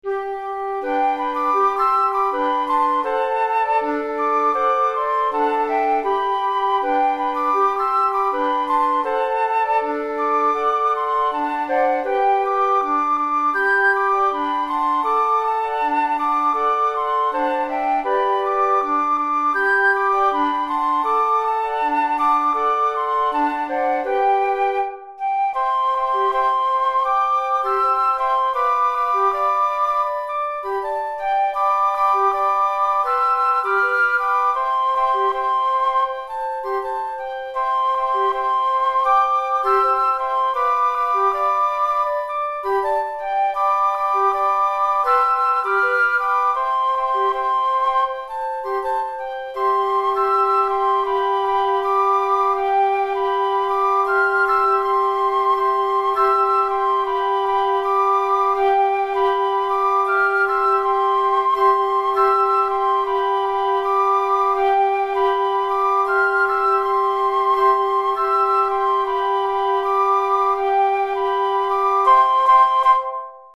4 Flûtes Traversières